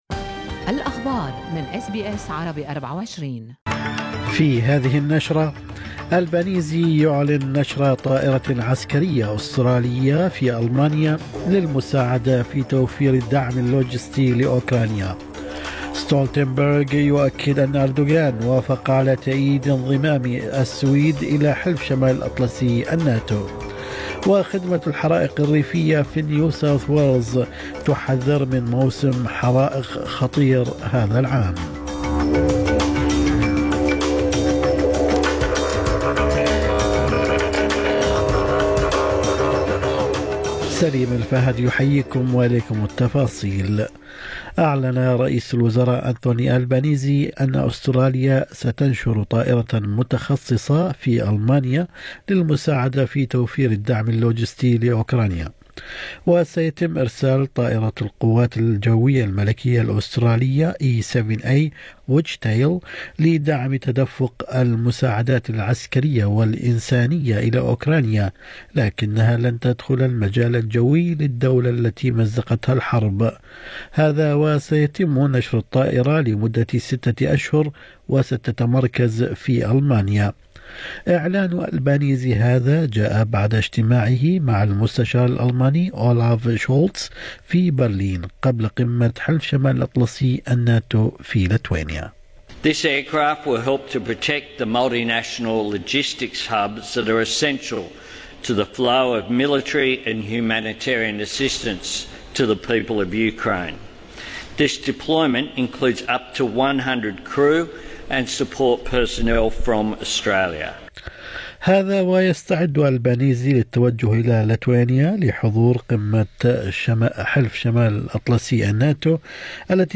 نشرة اخبار الصباح 11/7/2023